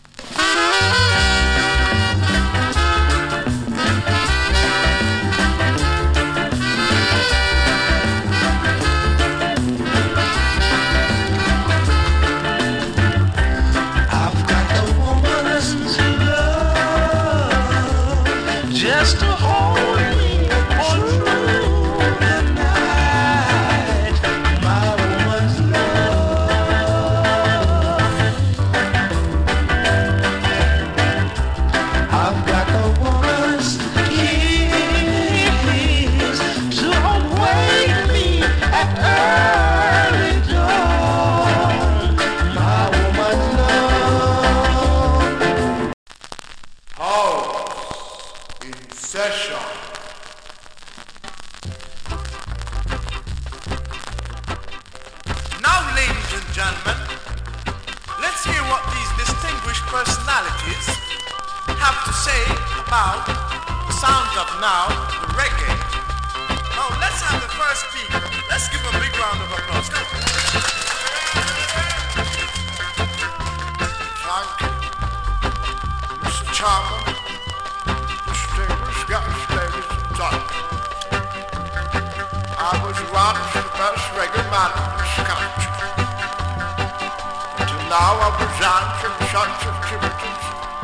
チリ、パチノイズ有り。
GREAT EARLY REGGAE.